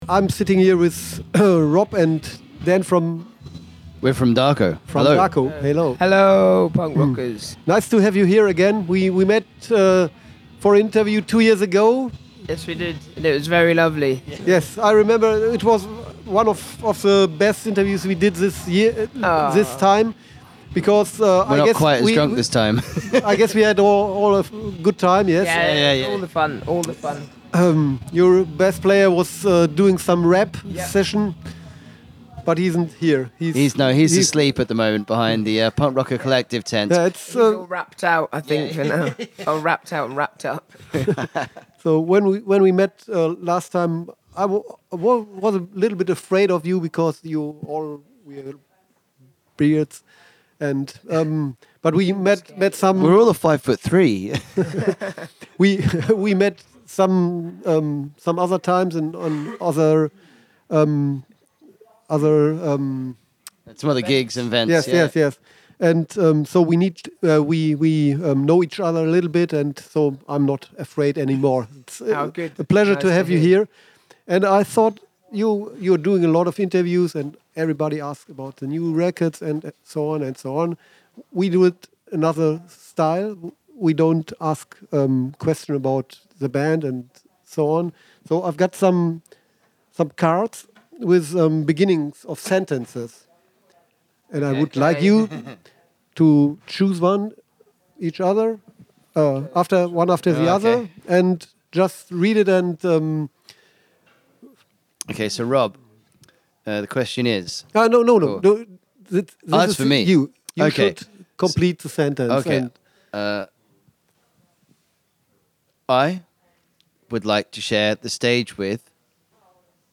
Letzte Episode Interview mit Darko @ Punk Rock Holiday 1.7 9.
interview-mit-darko-punk-rock-holiday-1-7-mmp.mp3